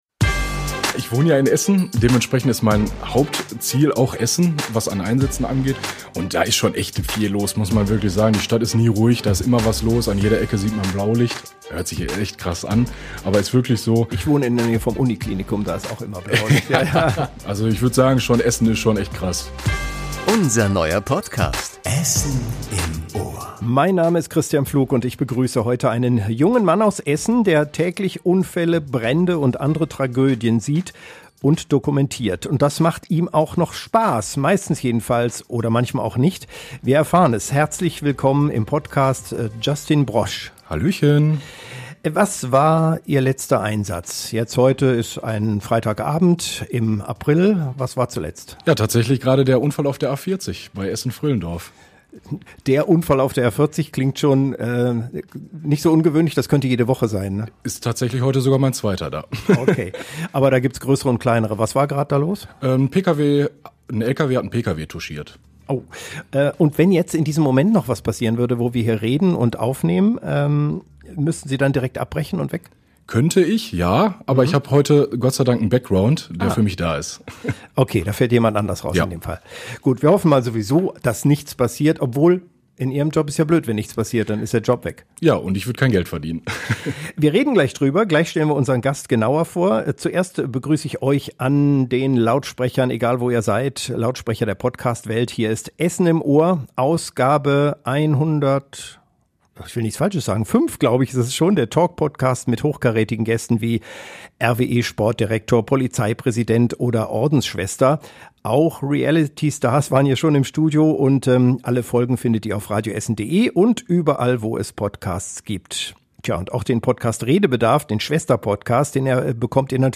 Der Talk mit Persönlichkeiten aus der Stadt